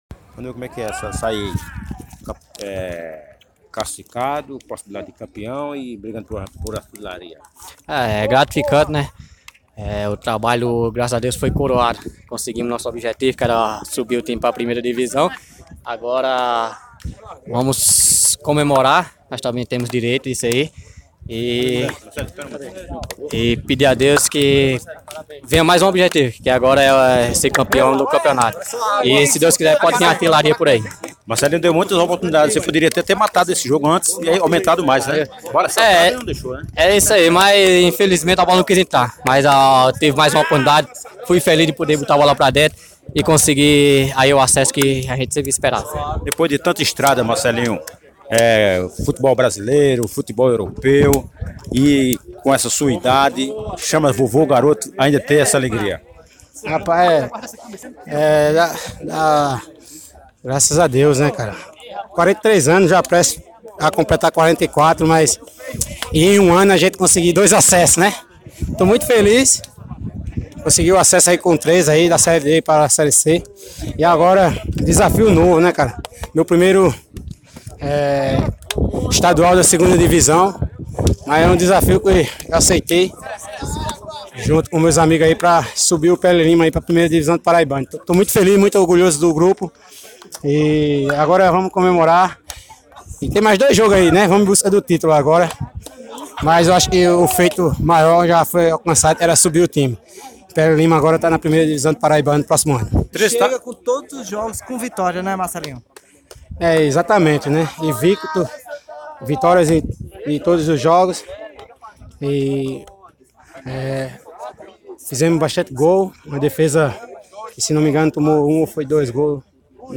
Depois da vitória, os jogadores da Perilima comemoram bastante, ainda no gramado do PV com os 2a0 sobre o Sport Lagoa Seca.